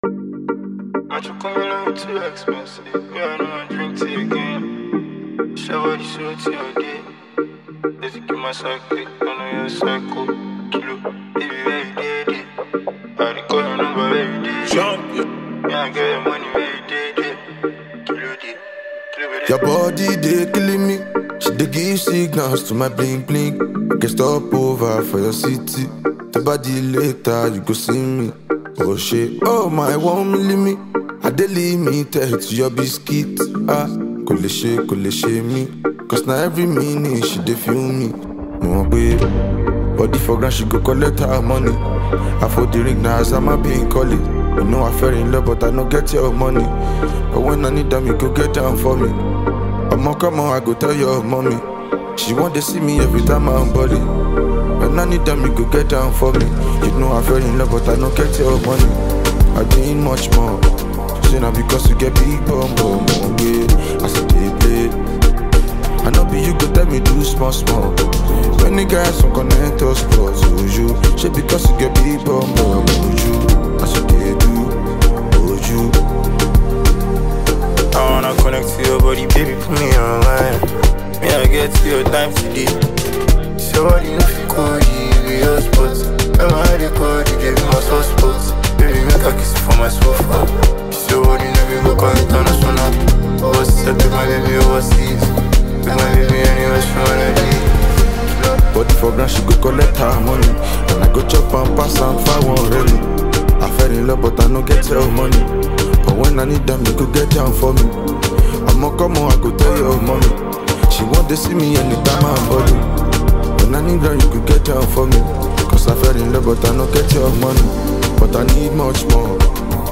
With vibrant production and an irresistible rhythm